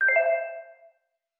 mixkit-unlock-game-notification-253.wav